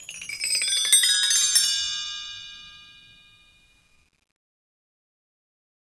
BellTree_Stroke4_v1_Sum.wav